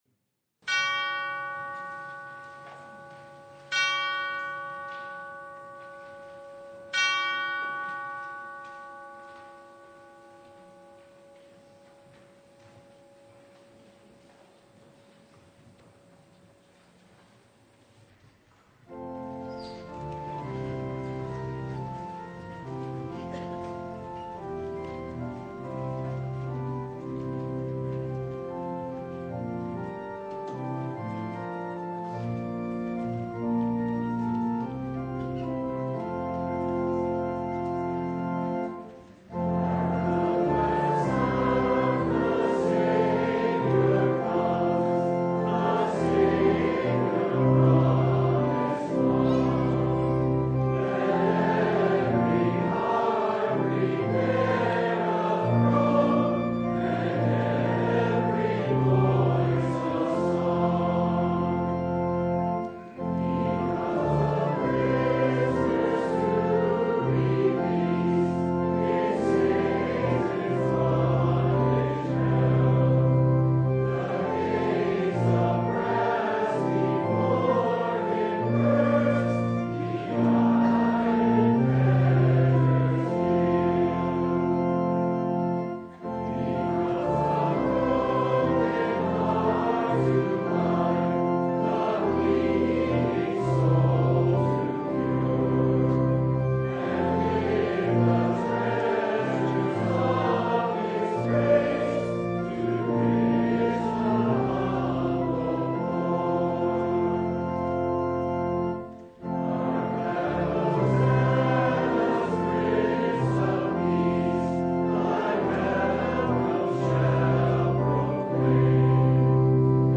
Luke 3:1-20 Service Type: Sunday How does one prepare for the coming of the Lord?